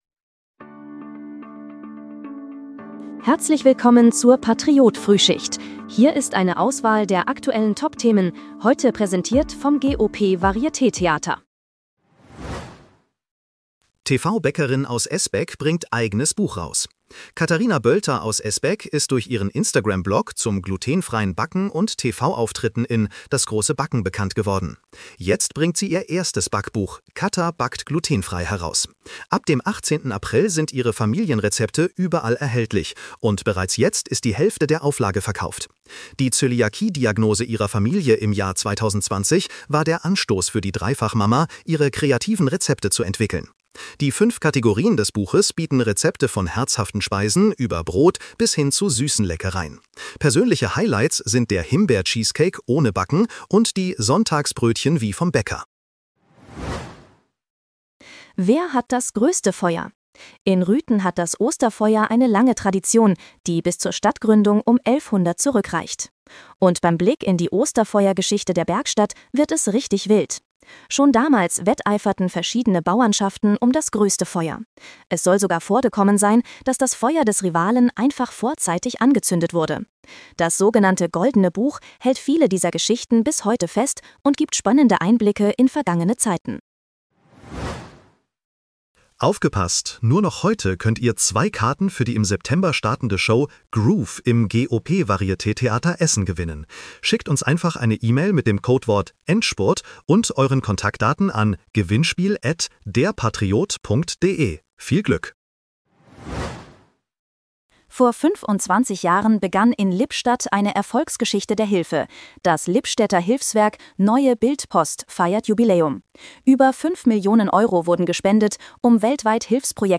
Dein morgendliches News-Update
mit Hilfe von Künstlicher Intelligenz.